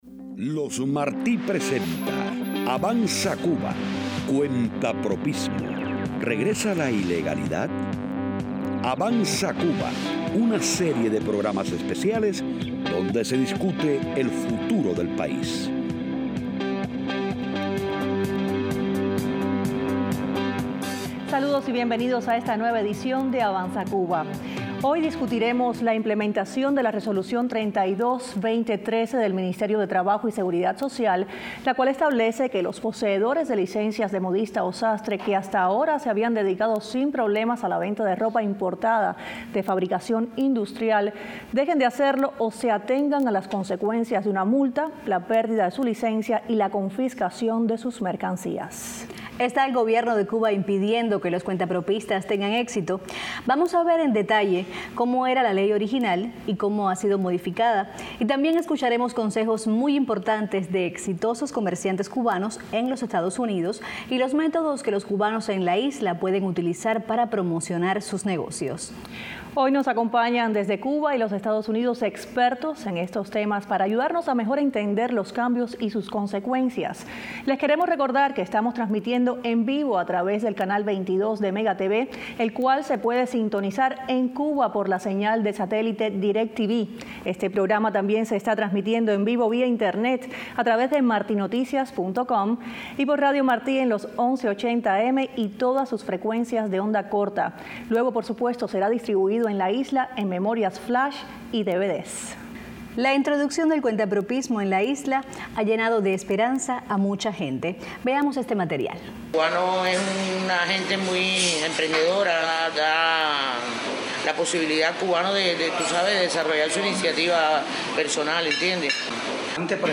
en los estudios de Marti y desde Cuba